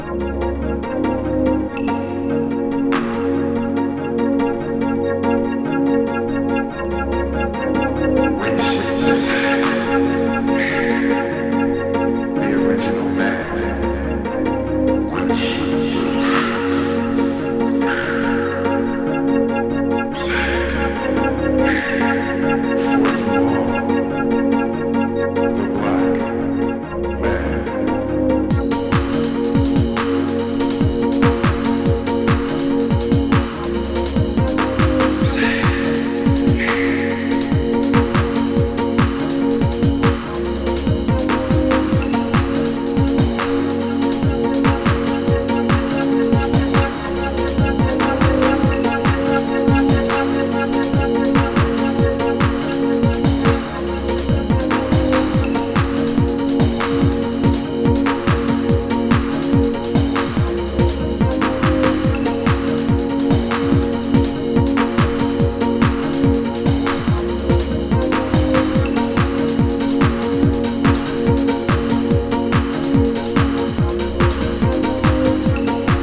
Ambient Techno〜Tranceトラック「?